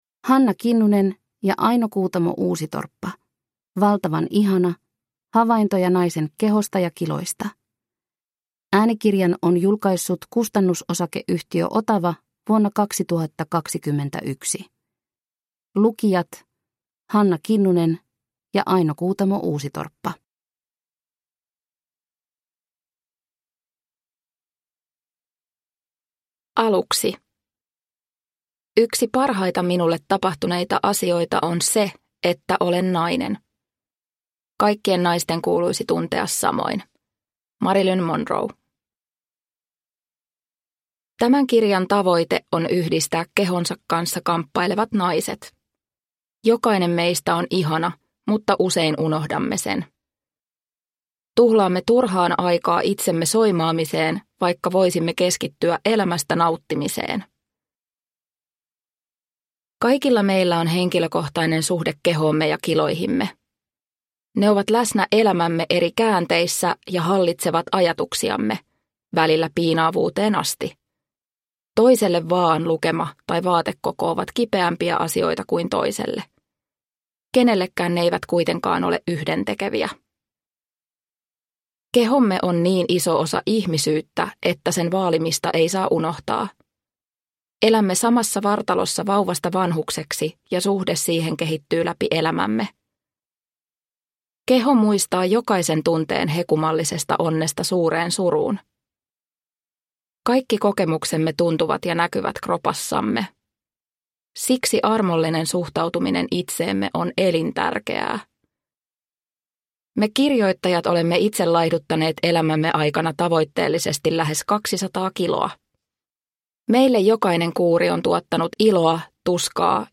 Valtavan ihana – Ljudbok – Laddas ner